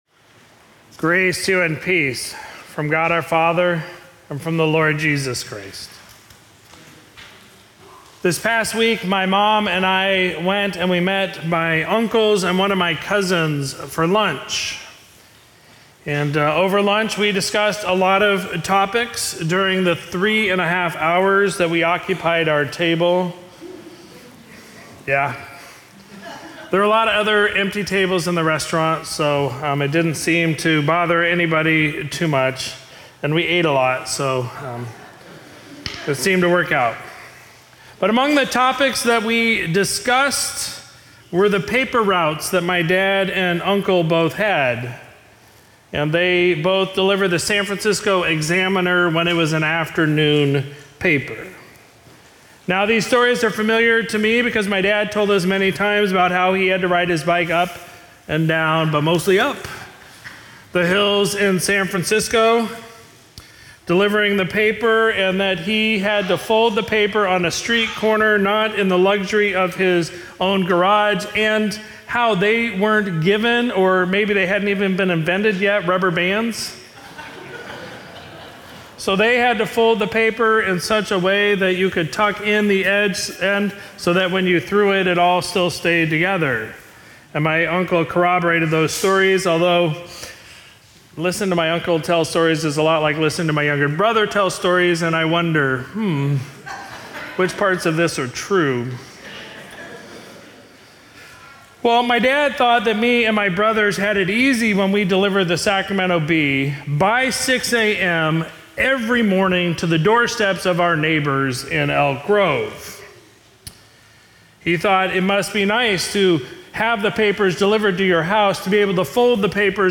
Sermon for Sunday, July 30, 2023